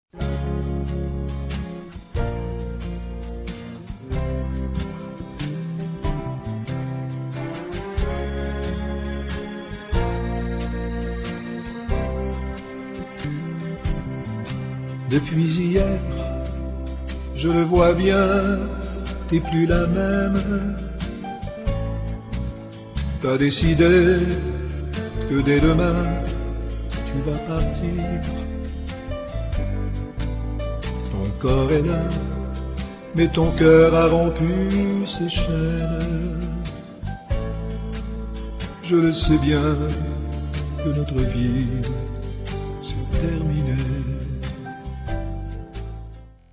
12 grands succès de la chanson Country
En version française